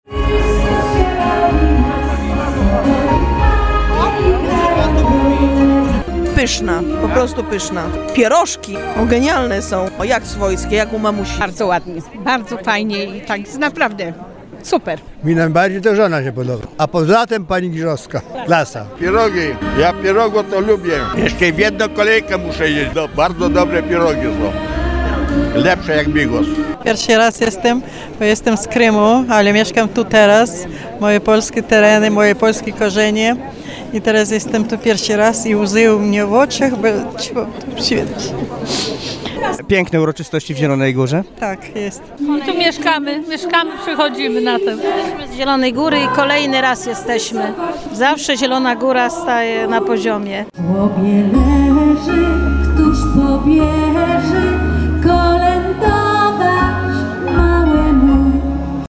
Wspólne śpiewanie kolęd, życzenia świąteczne, a na stole pierogi i pyszny bigos oraz barszcz. Tak bawili się wczoraj zielonogórzanie podczas miejskiej wigilii przy ratuszu.